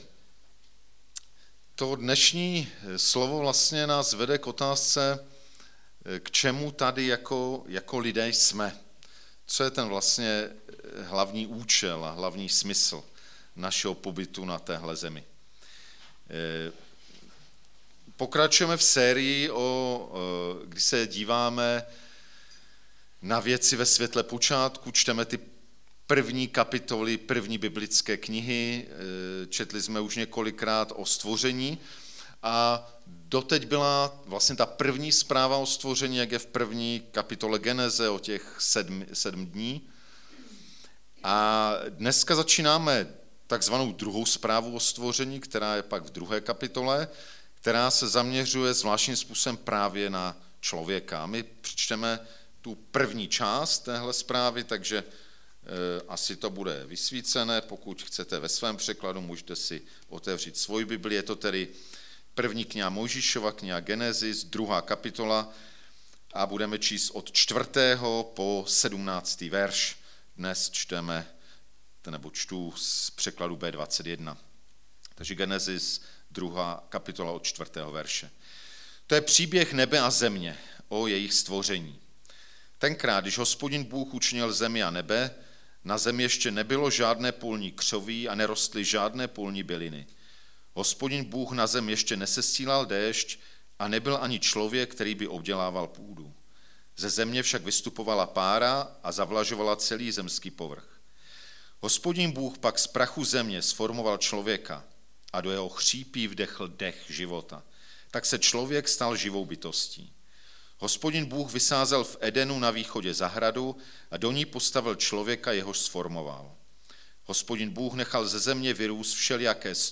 5. díl ze série kázání "Ve světle počátku", Gn 2,4-17
Kategorie: nedělní bohoslužby